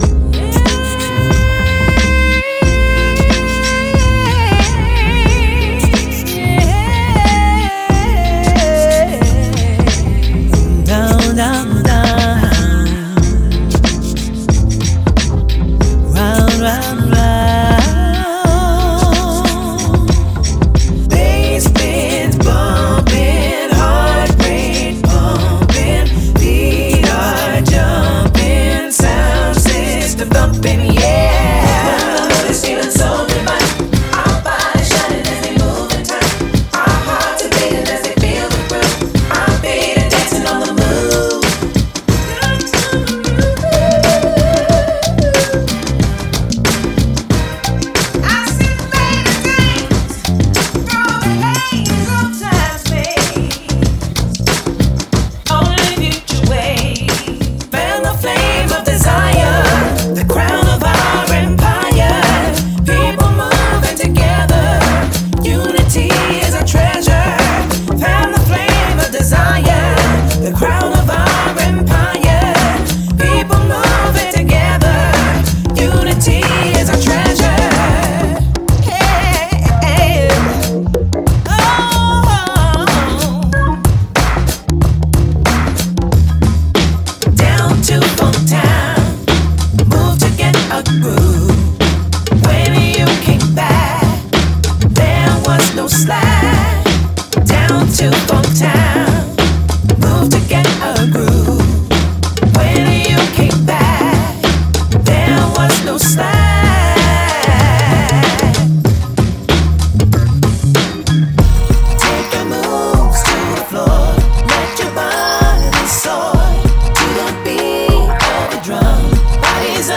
Genre:Soul
すべてのループはヴィンテージスタイルの機材とクリエイティブFXを通して加工され、複数のフレーバーを提供します。
LoFi – 温かみがあり、ほこりっぽくグリッティな質感
Echo – 幅広く広がるアンビエンス
Harmonizer – 70年代ヴィンテージのボーカルトリートメント
Vocoder – ロボットファンク/ソウルやレトロフューチャーな雰囲気に最適
228 Dry Vocal Loops
1633 FX Processed Vocal Loops